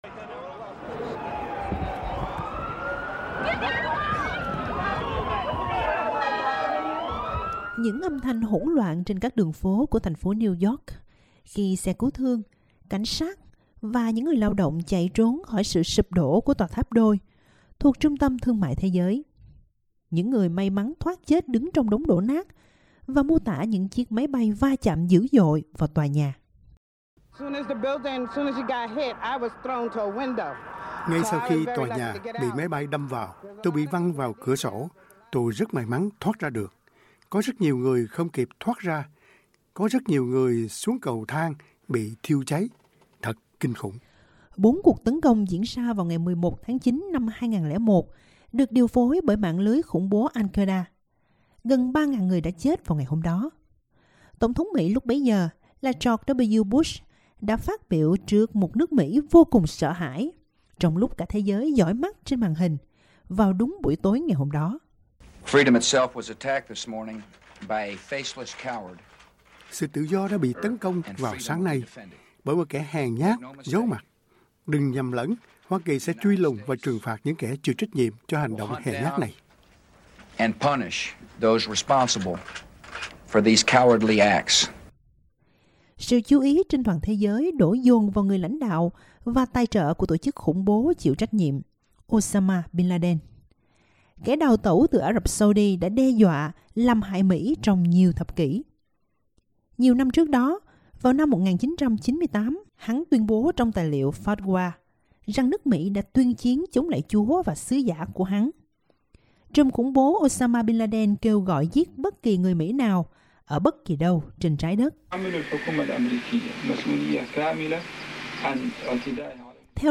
Những âm thanh hỗn loạn trên các đường phố của thành phố New York, khi xe cứu thương, cảnh sát và những người lao động chạy trốn khỏi sự sụp đổ của tòa tháp đôi của Trung tâm Thương mại Thế giới.